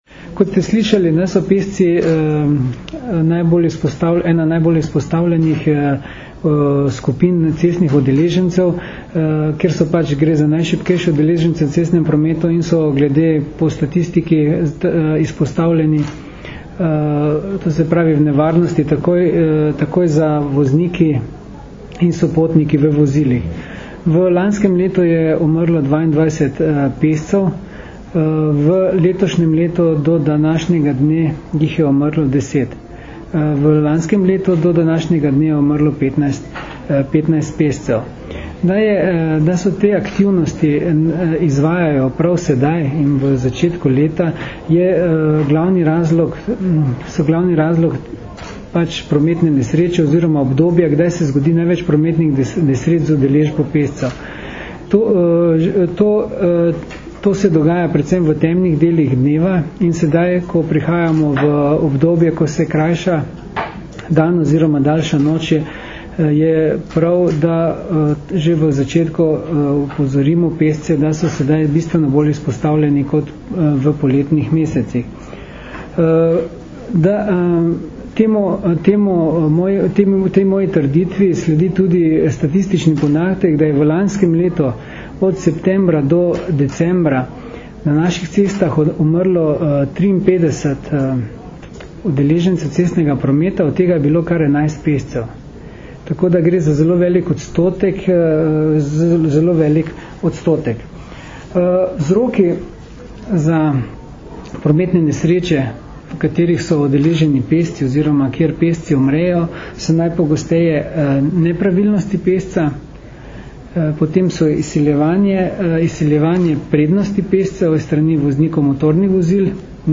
Na današnji novinarski konferenci, ki jo je organizirala Javna agencija RS za varnost prometa, smo predstavili akcijo za večjo varnost pešcev Bodi preViden, ki bo potekala med 1. in 14. oktobrom 2012.
Zvočni posnetek izjave mag.